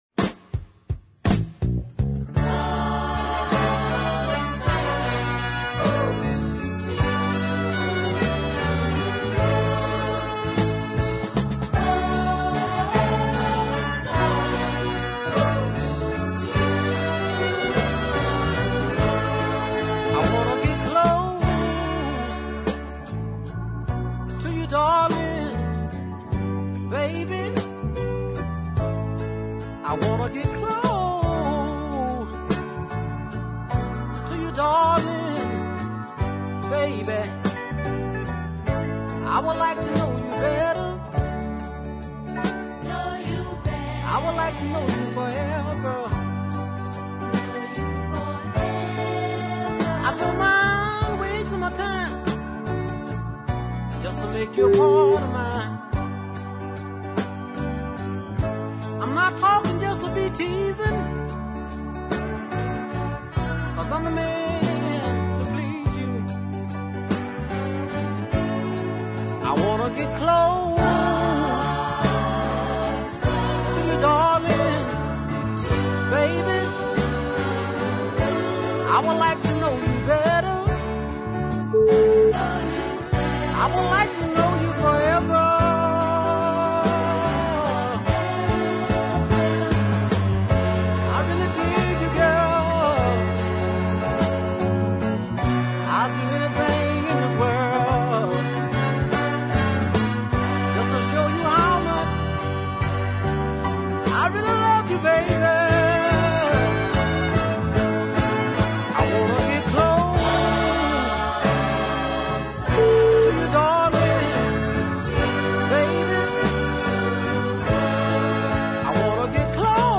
Light voiced Georgian singer
throwback styled sides
wonderfully melodic ballad
love the climbing horn section too.